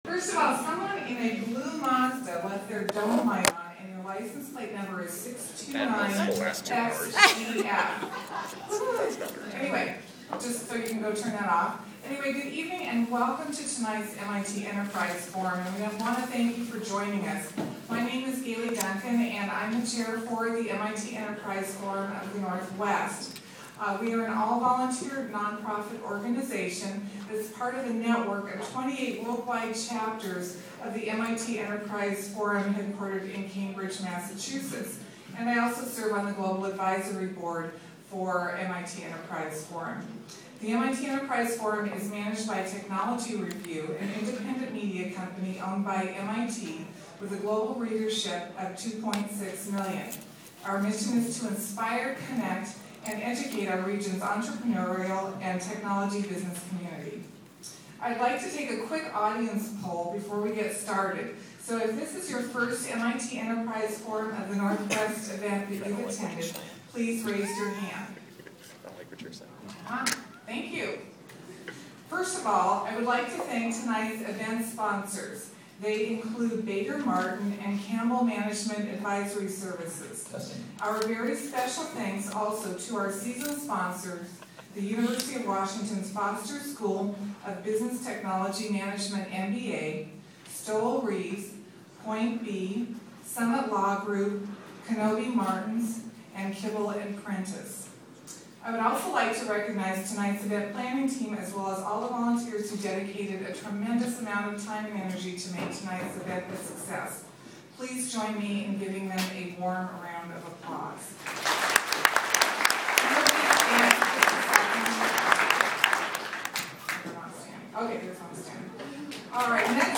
full audio of the introduction for context):
I didn’t… that was my nervous laughter in the background of the recording.